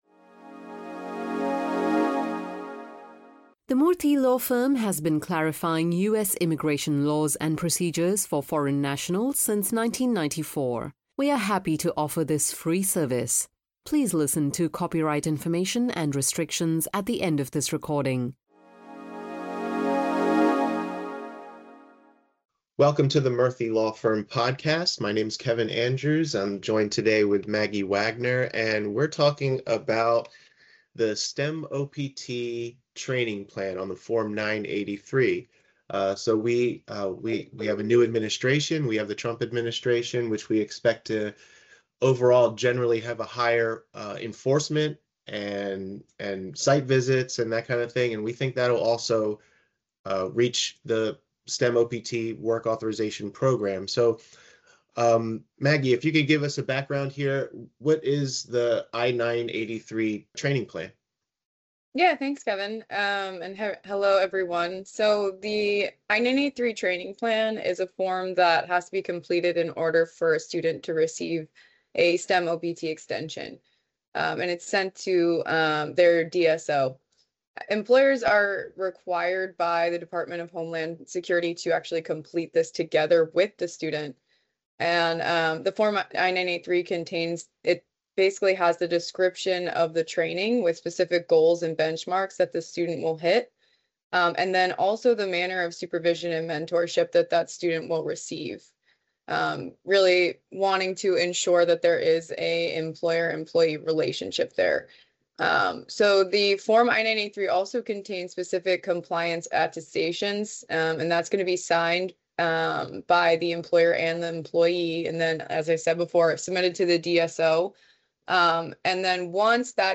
The STEM OPT training plan and how employers and employees can comply with its requirements are discussed by Murthy Law Firm attorneys in this podcast.